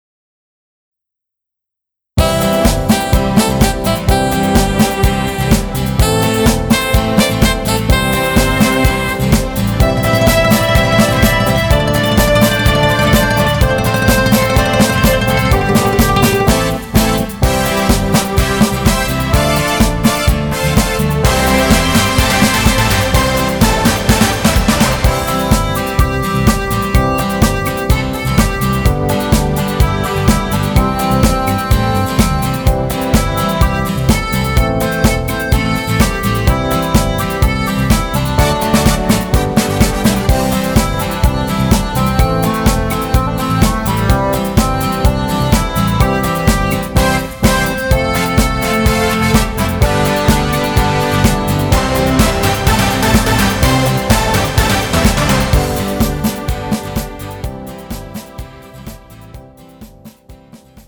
음정 남자-2키
장르 가요 구분 Pro MR